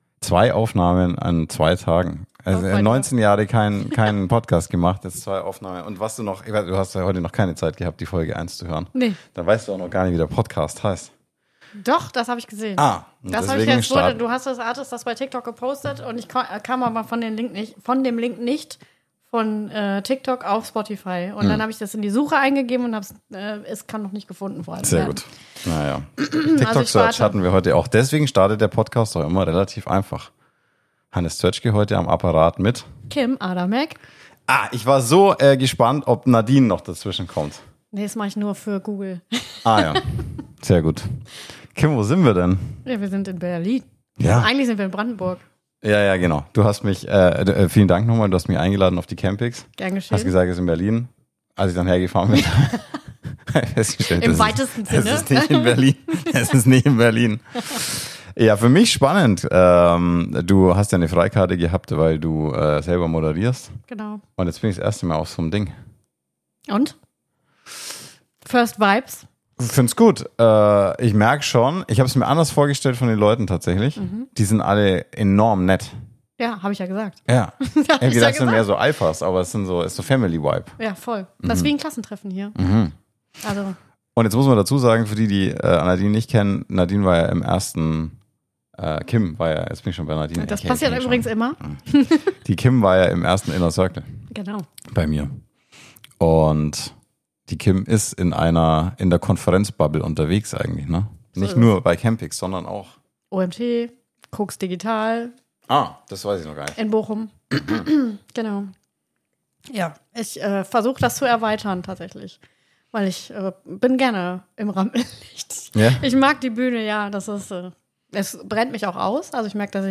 In meinem Fall auf der CAMPIXX, wo auch dieser Podcast entstanden ist.